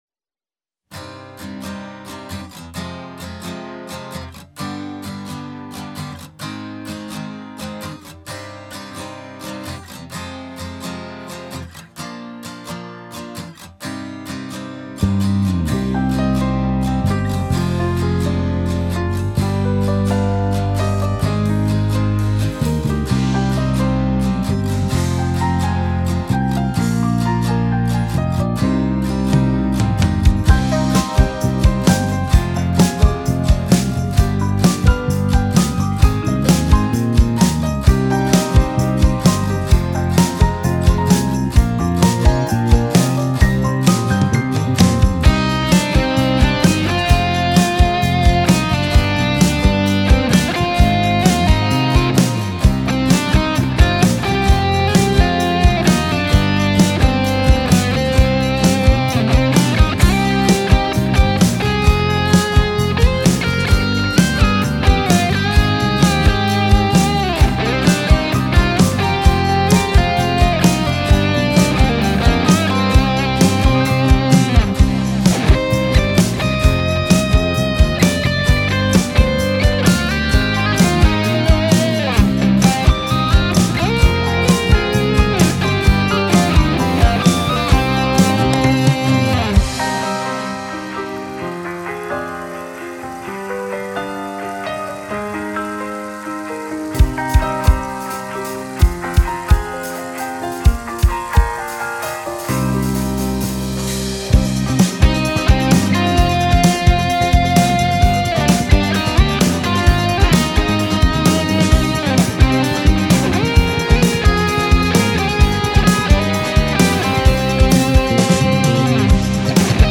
عمیق و تامل برانگیز
موسیقی بی کلام راک